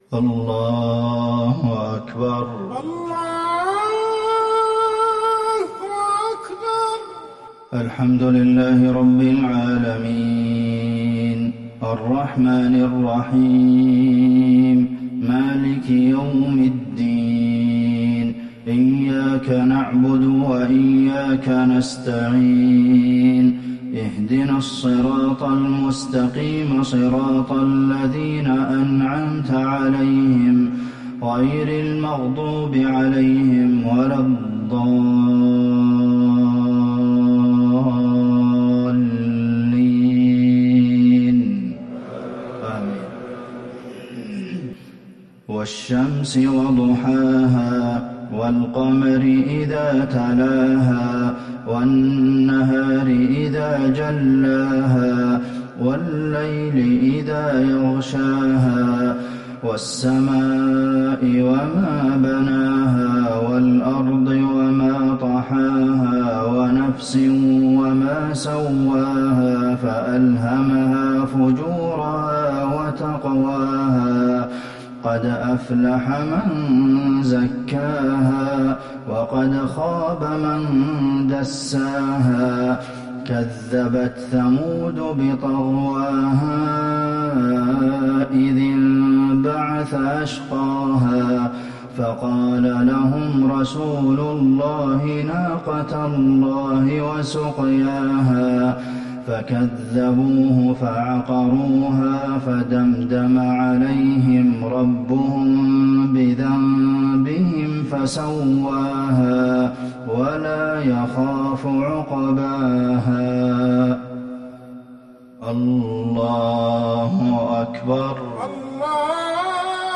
صلاة المغرب للشيخ عبدالمحسن القاسم 20 جمادي الأول 1441 هـ
تِلَاوَات الْحَرَمَيْن .